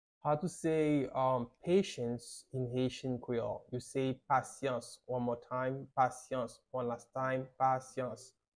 How to say "Patience" in Haitian Creole - "Pasyans" pronunciation by a native Haitian tutor
“Pasyans” Pronunciation in Haitian Creole by a native Haitian can be heard in the audio here or in the video below:
How-to-say-Patience-in-Haitian-Creole-Pasyans-pronunciation-by-a-native-Haitian-tutor.mp3